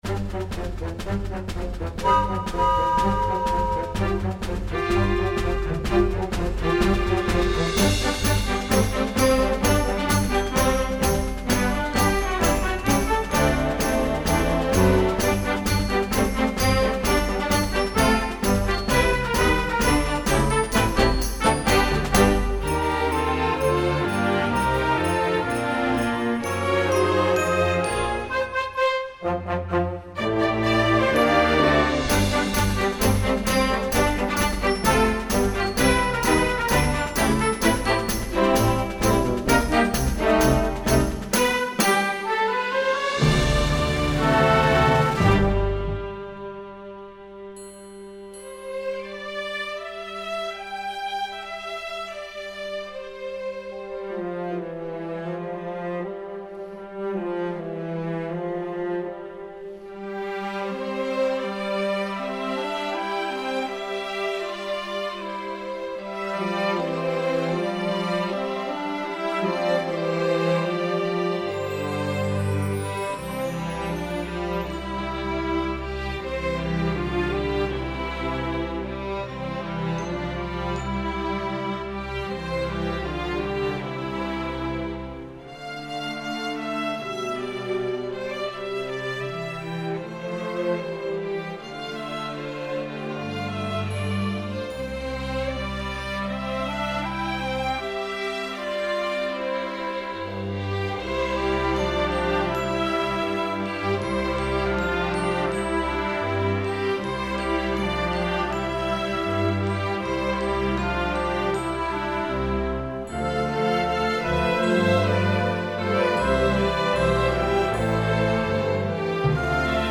Instrumentation: full orchestra
instructional, children